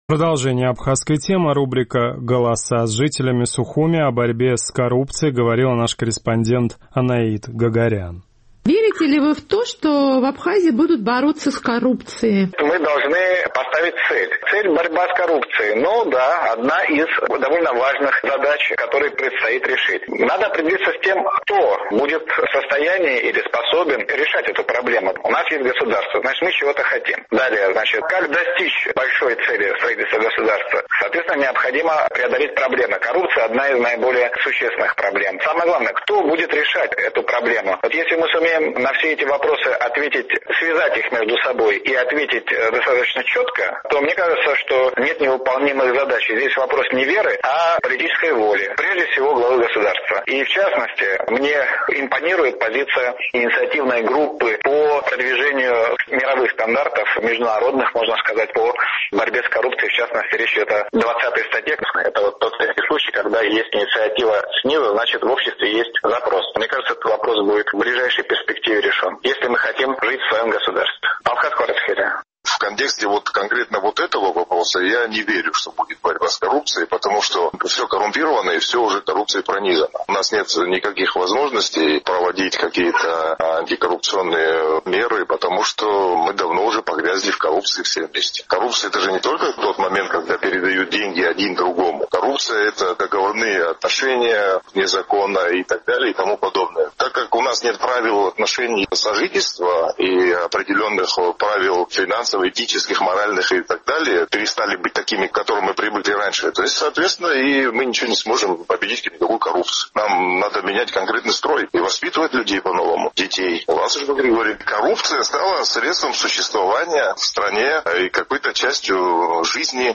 О борьбе с коррупцией в Абхазии говорят все политики, но есть ли какие-то реальные действия? Судя по ответам в нашем традиционном опросе, мало кто верит, что она начнется в обозримом будущем.